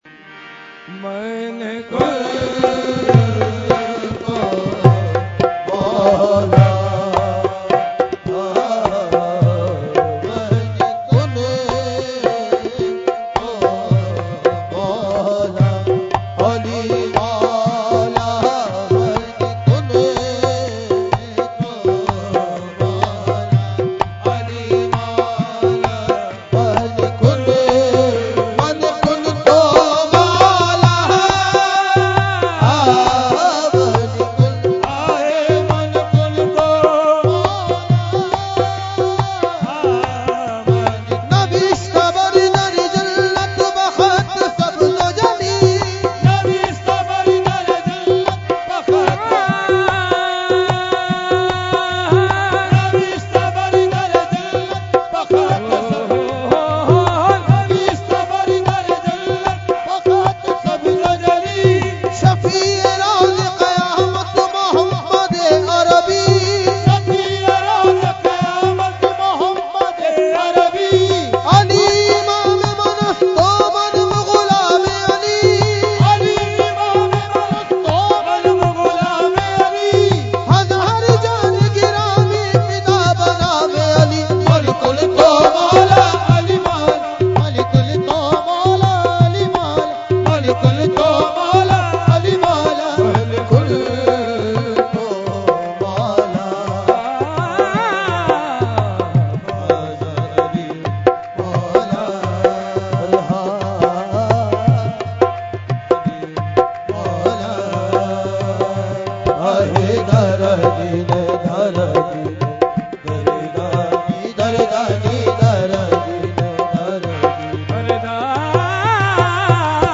Category : Qawali | Language : UrduEvent : Urs Qutbe Rabbani 2018